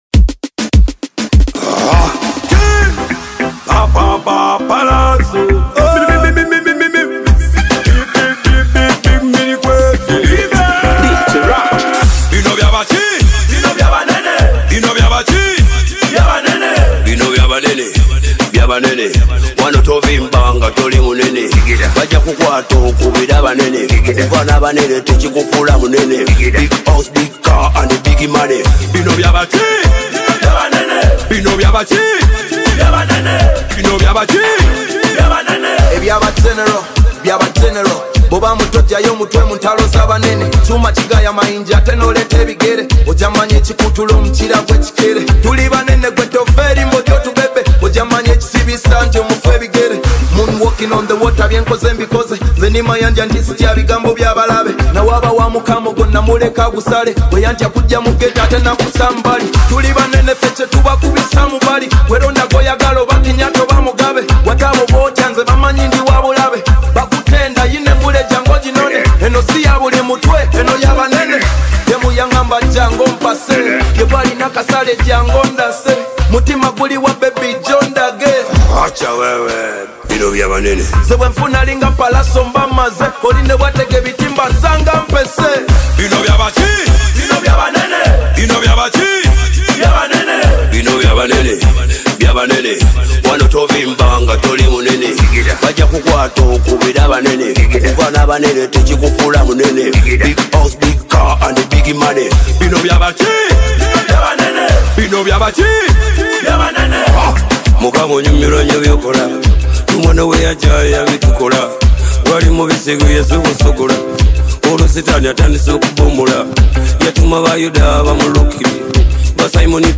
is a high-energy track packed with talent and star power